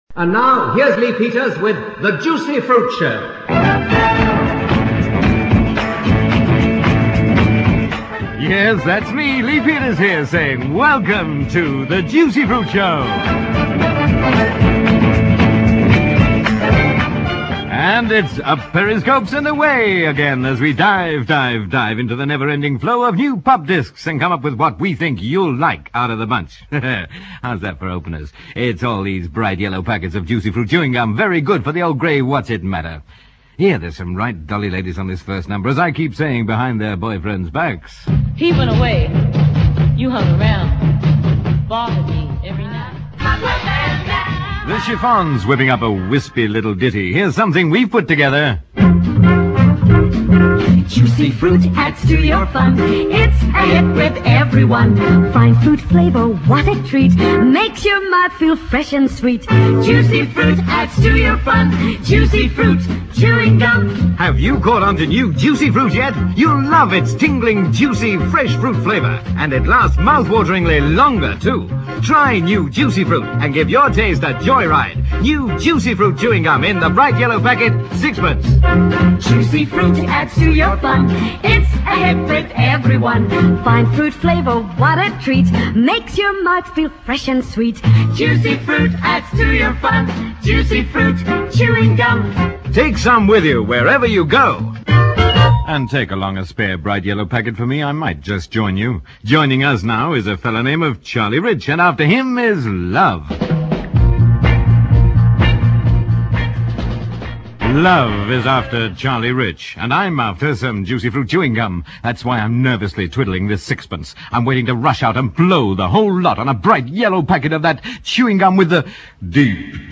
A studio recording